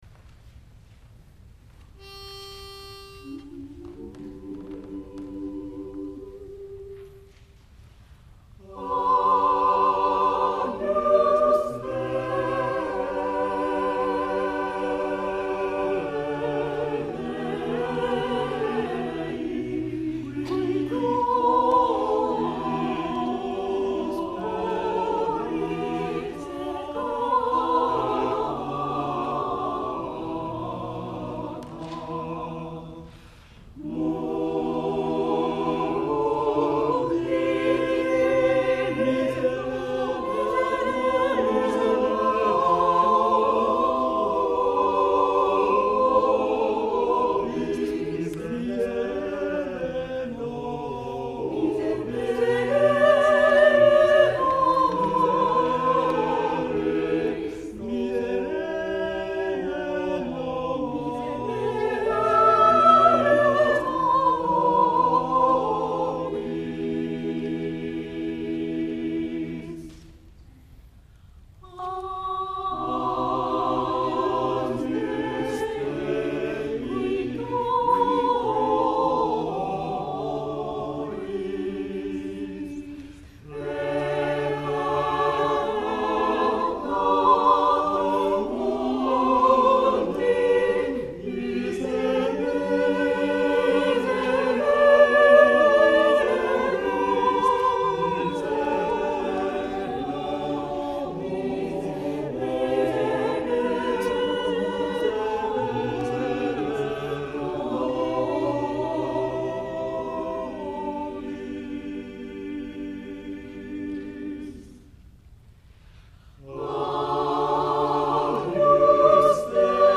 第38回野田市合唱祭
野田市文化会館
Mass for four voices より　Thomas Tallis　タリス